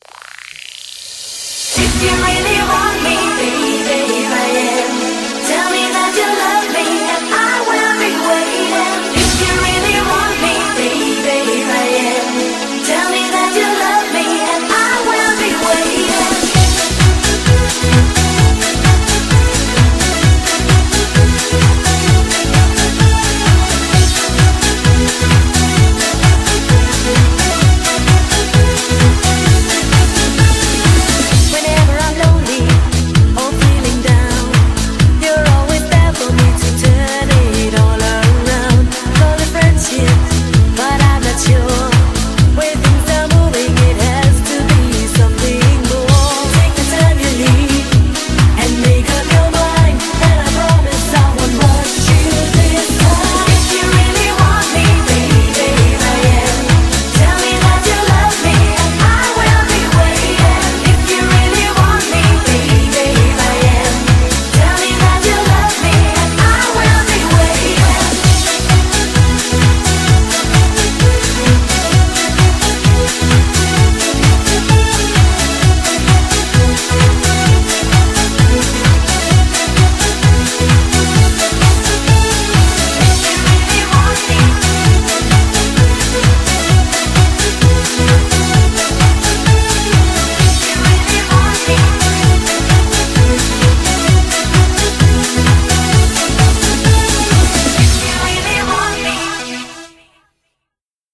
BPM130
Audio QualityPerfect (High Quality)
The song has a very simple melody with little complications.